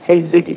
synthetic-wakewords
ovos-tts-plugin-deepponies_Kanye West_en.wav